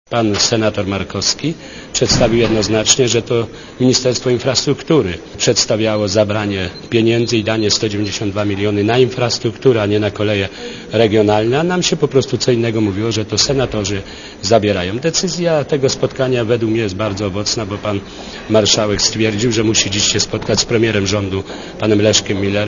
Dla Radia Zet mówi Stanisław Kogut (88 KB)